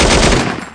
Machine Gun Sound #1
i'm using Portable GoldWave, MG Sound was recorded from Some Movies, but i cant' remember what movie it is, cuz that MG Sound was made long time ago and i've just edit them yesterday.